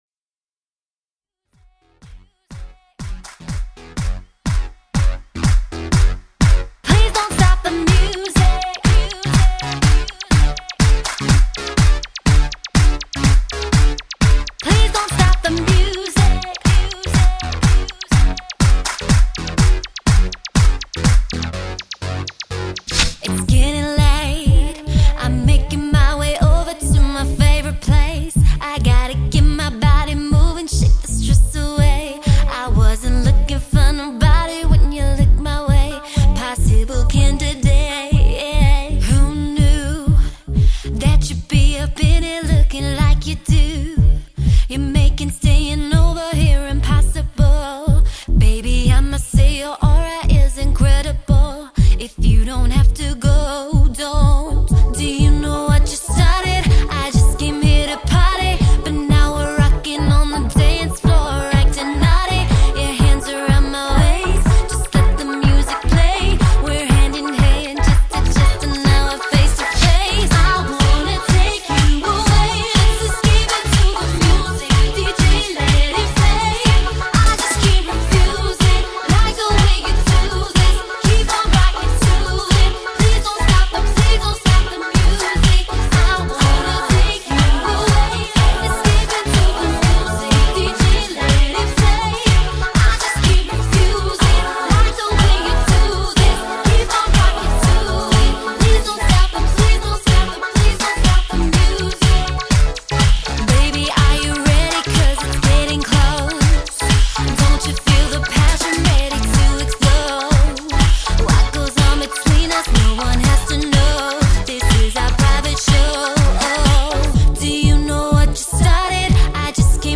With male & female lead vocals